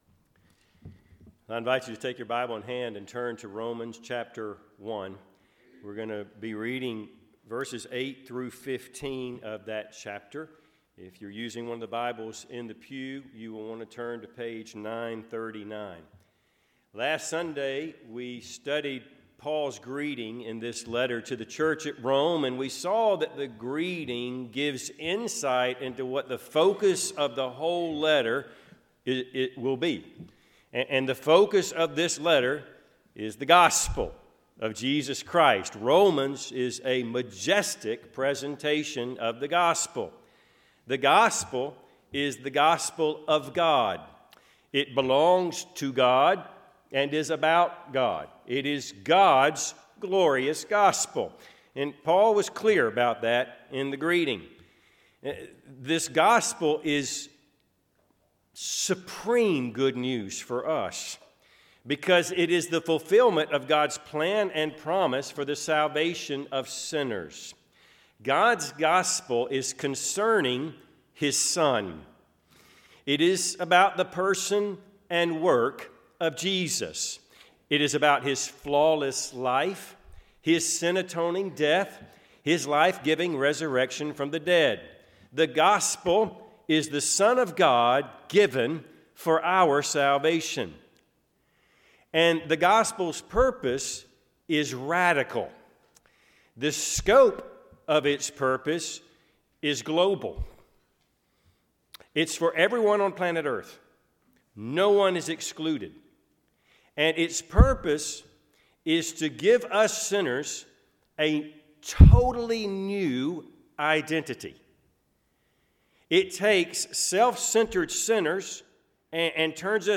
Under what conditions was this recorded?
Passage: Romans 1:8-15 Service Type: Sunday AM